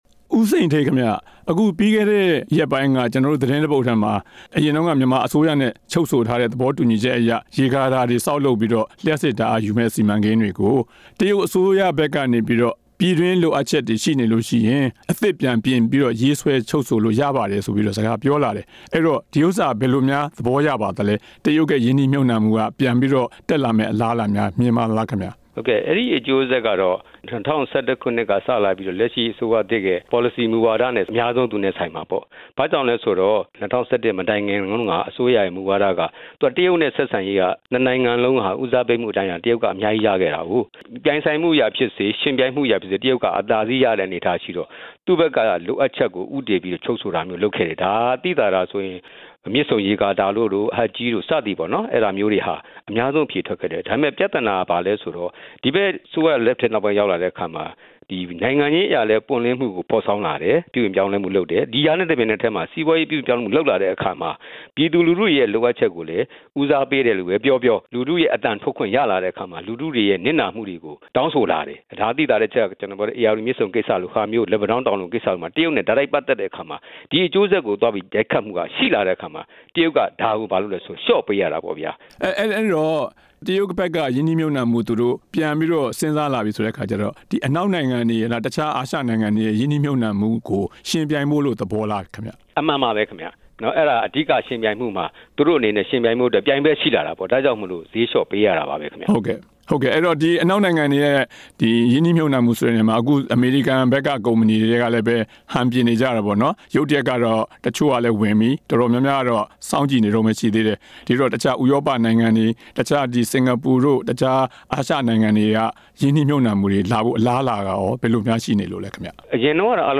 မြန်မာပြည်မှ တရုတ်ရင်းနှီးမြှုပ်နှံမှုများကိစ္စ မေးမြန်းချက်